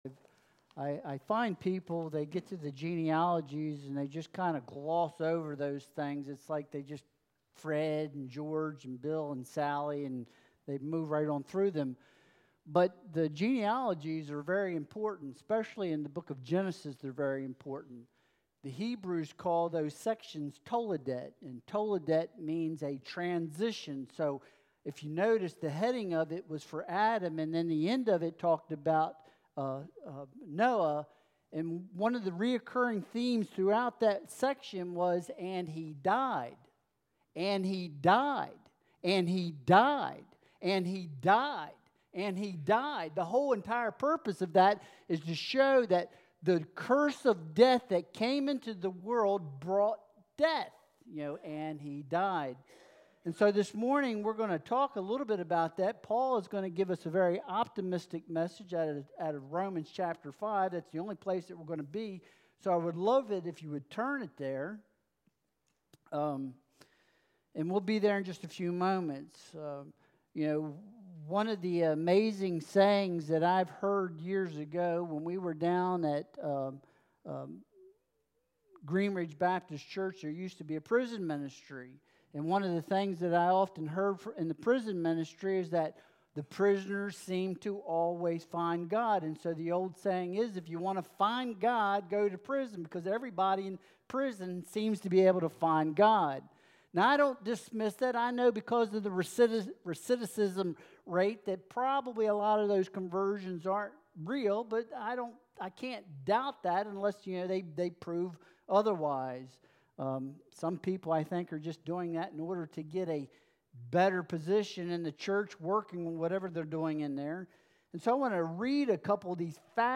Romans 5.12-20 Service Type: Sunday Worship Service Download Files Bulletin « Is It Right for Everyone?